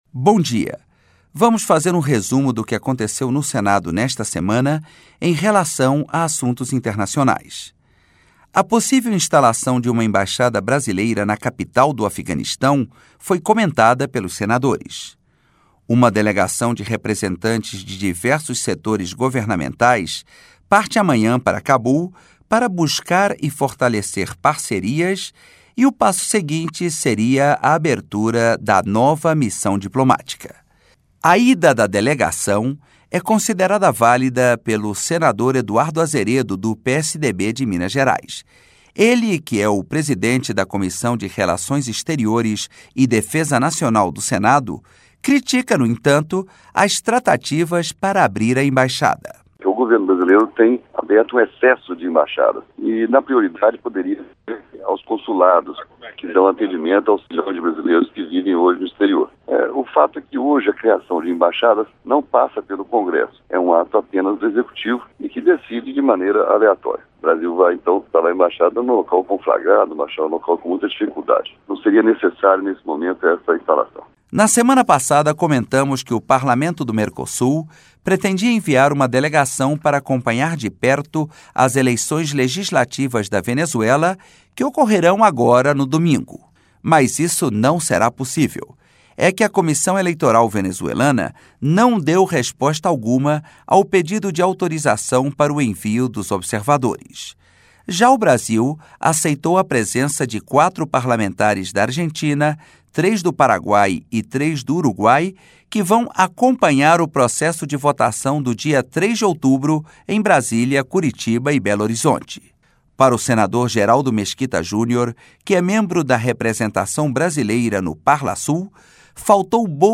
Programa diário com reportagens, entrevistas e prestação de serviços
Senadores comentam instalação da embaixada brasileira no Afeganistão